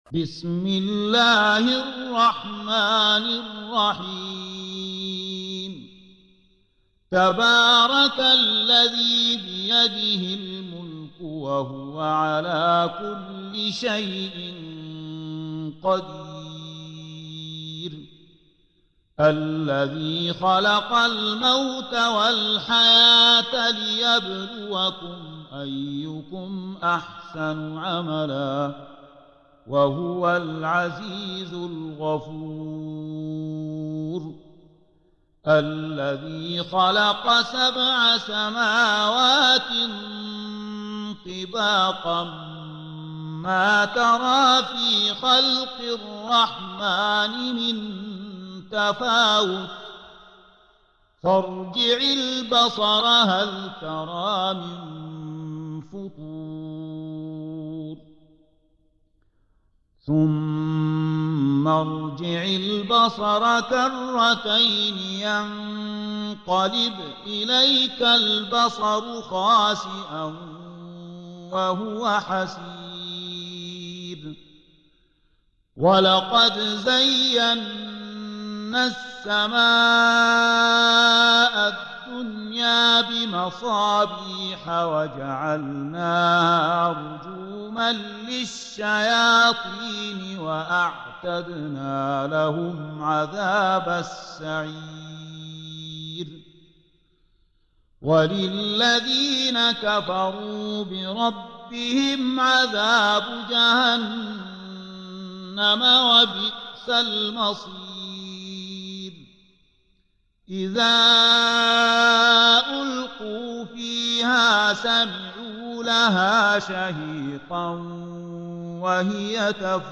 67. Surah Al-Mulk سورة الملك Audio Quran Tarteel Recitation
Surah Sequence تتابع السورة Download Surah حمّل السورة Reciting Murattalah Audio for 67. Surah Al-Mulk سورة الملك N.B *Surah Includes Al-Basmalah Reciters Sequents تتابع التلاوات Reciters Repeats تكرار التلاوات